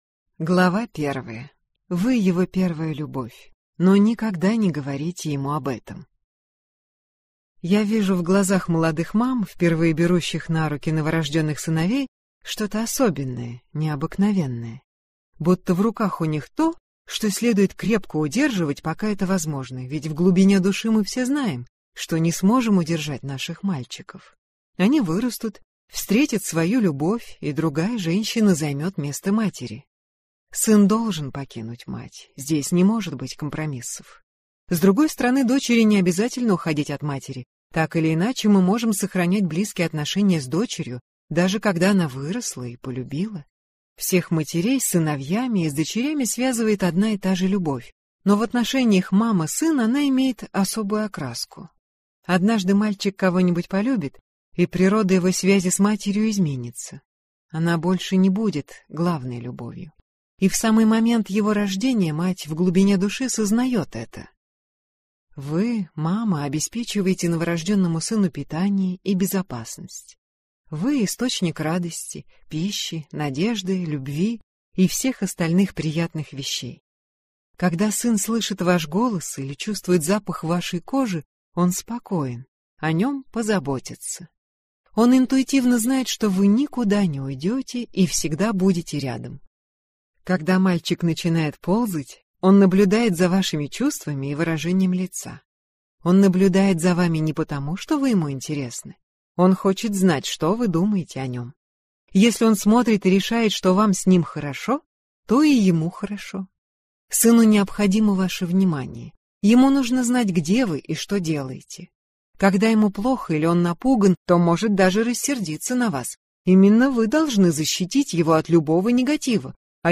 Аудиокнига Мама и сын. Как вырастить из мальчика мужчину | Библиотека аудиокниг